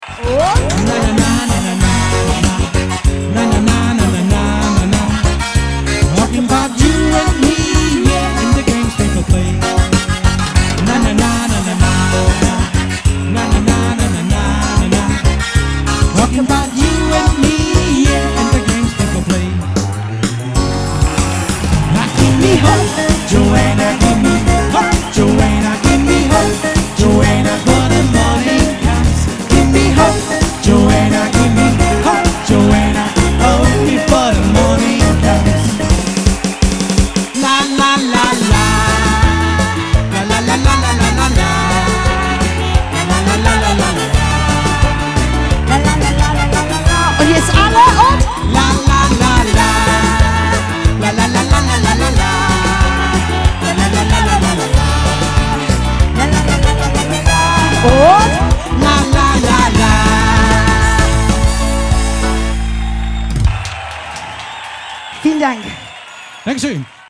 • Reggae-Surf-Mix (diverse)